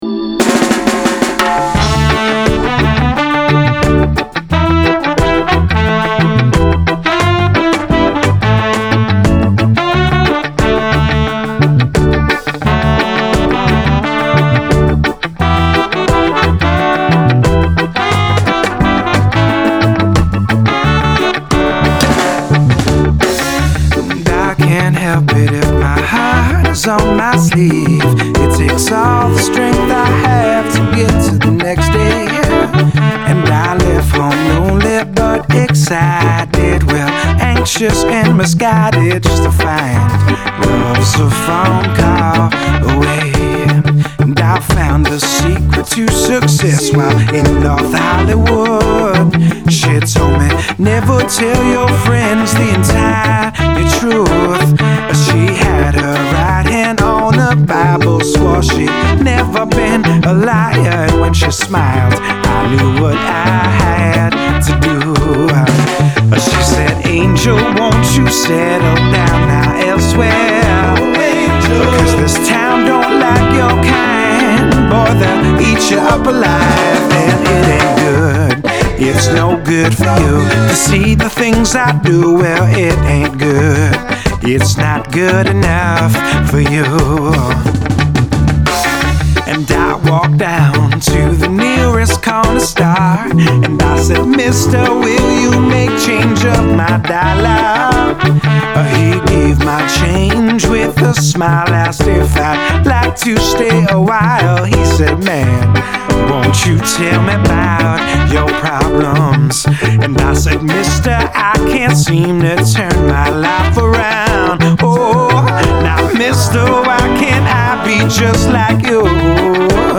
reggae ska